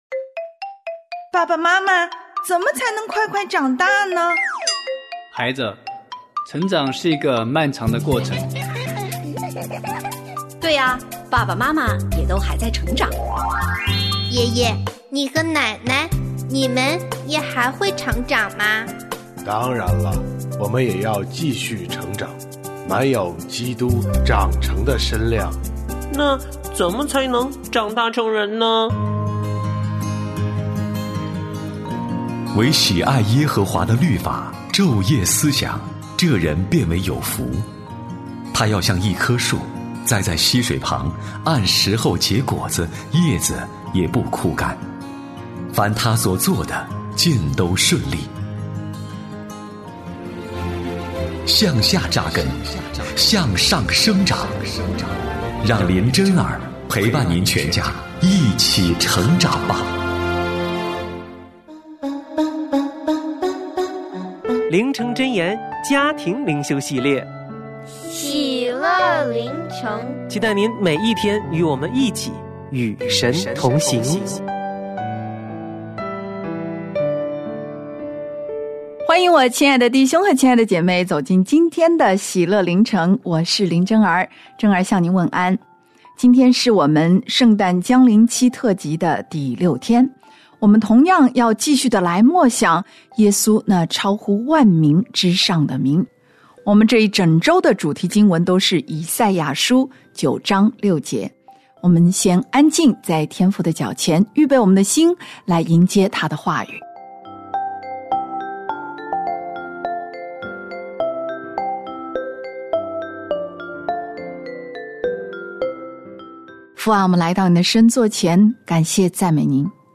我家剧场：圣经广播剧（143）书念妇人接待以利沙；以利沙行神迹